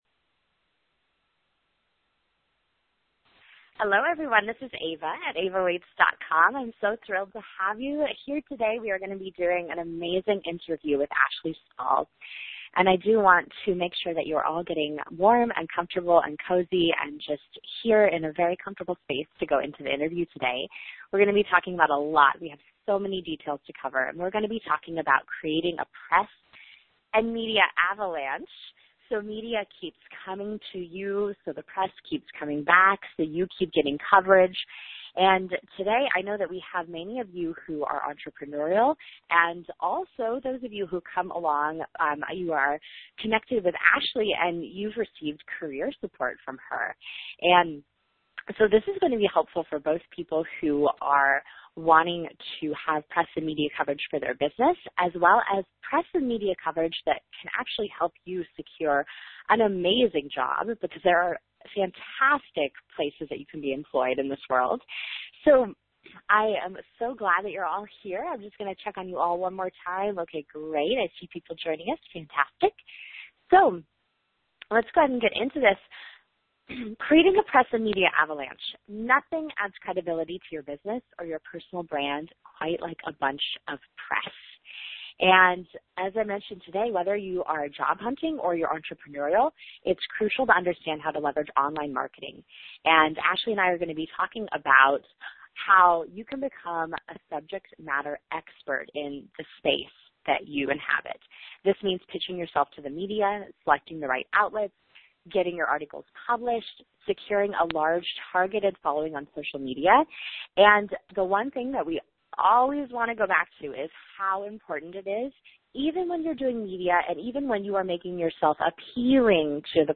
Enjoy this interview with Career Coach